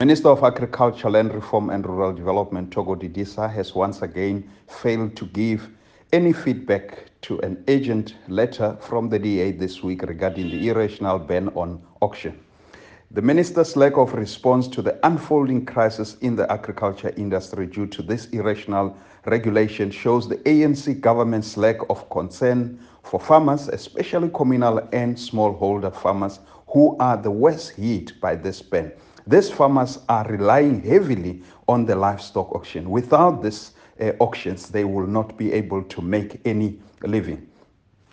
soundbite by Noko Masipa MP.